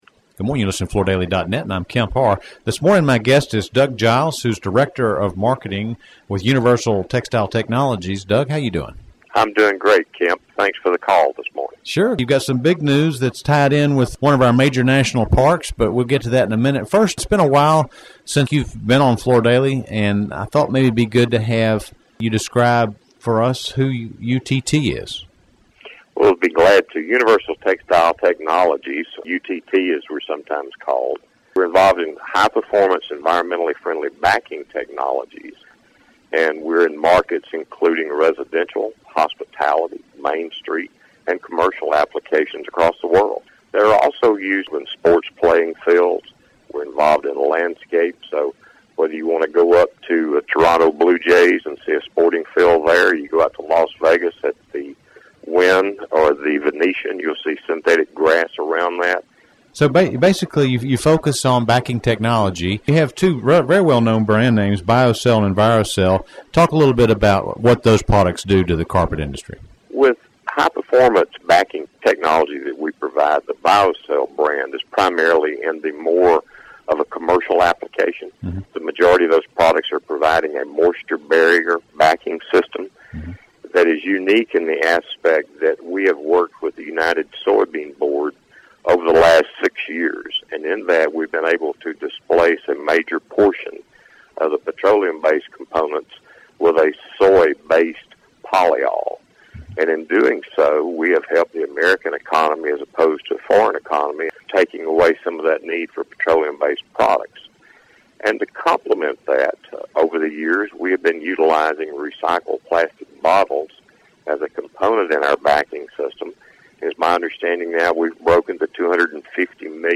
Listen to the interview to hear details around how UTT recycles Yellowstone's plastic bottles into high performance backing products for the residential, commercial and synthetic turf markets.